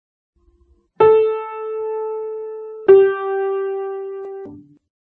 per (b) la nota Sol ascolta - - >
(b) rappresenta la tonica del dettato che dobbiamo scrivere (in questo esempio Sol).